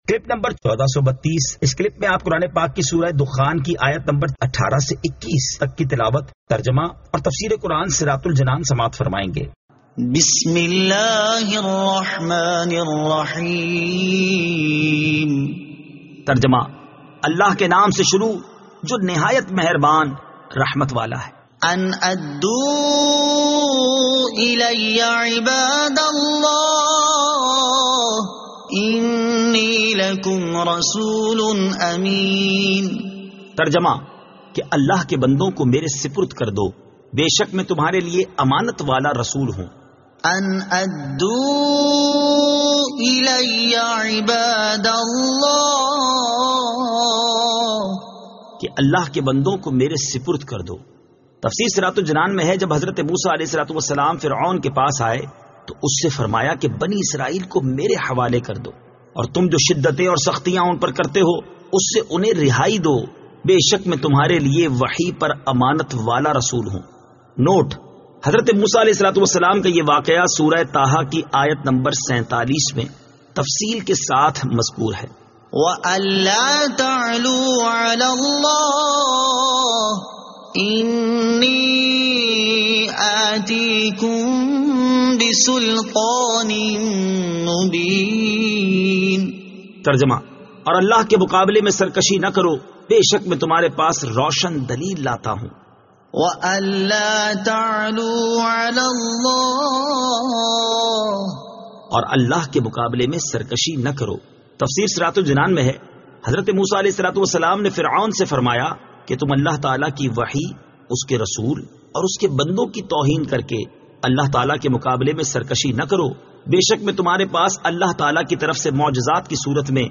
Surah Ad-Dukhan 18 To 21 Tilawat , Tarjama , Tafseer